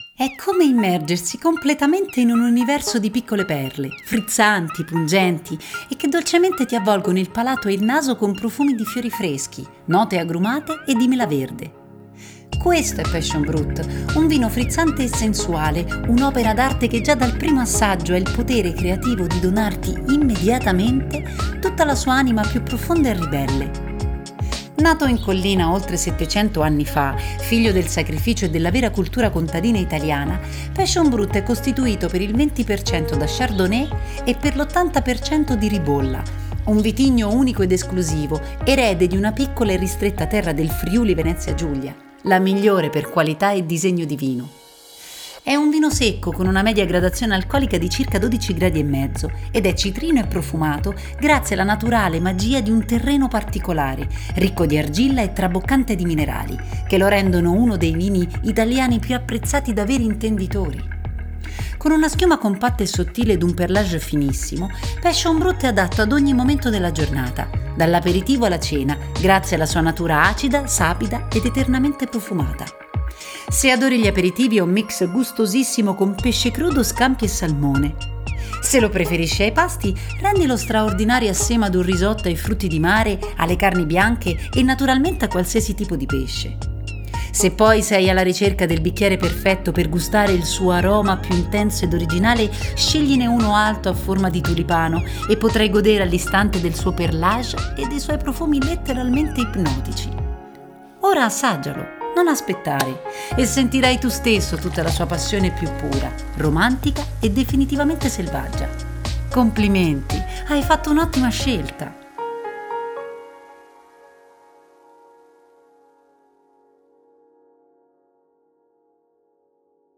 CLICCA QUI PER LA TUA DEGUSTAZIONE RITMATA E COINVOLGENTE!